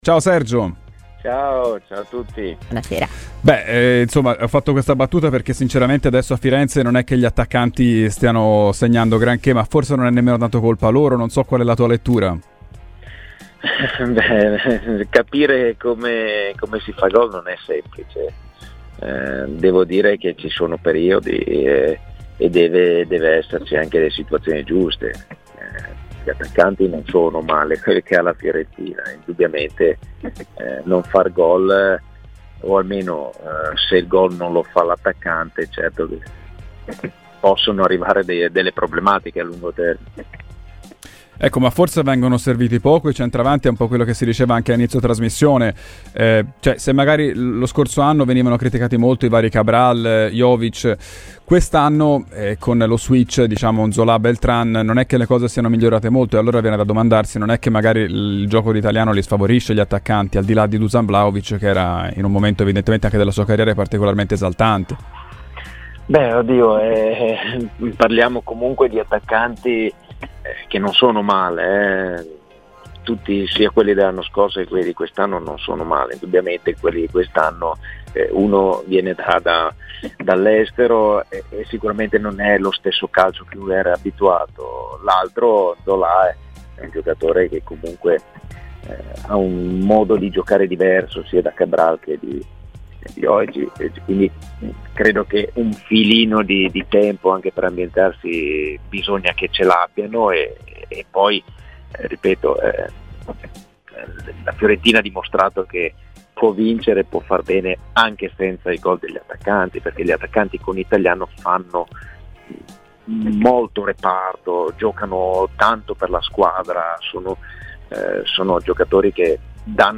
L'ex attaccante del Chievo, oggi presidente della Clivense neopromossa in serie D, Sergio Pellissier è intervenuto a Radio FirenzeViola durante la trasmissione "Social Club" per parlare in modo particolare della situazione attaccanti della squadra di Vincenzo Italiano.